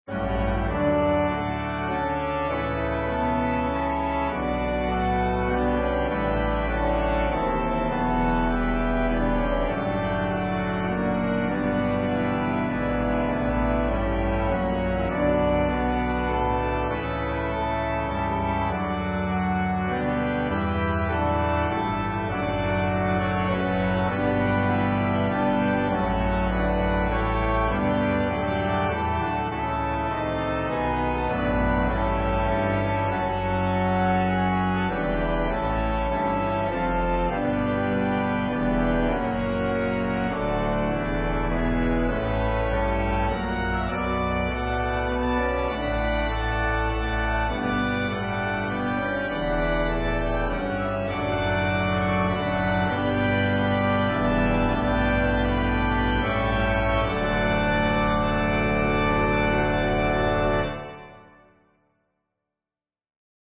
An organ accompaniment reharmonization of the last verse
Voicing/Instrumentation: Organ/Organ Accompaniment